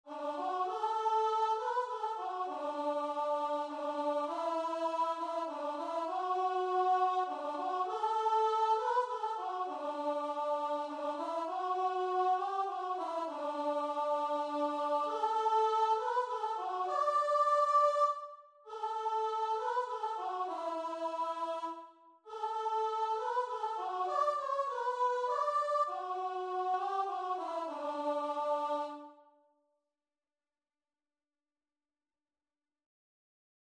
Christian
3/4 (View more 3/4 Music)
Guitar and Vocal  (View more Easy Guitar and Vocal Music)
Classical (View more Classical Guitar and Vocal Music)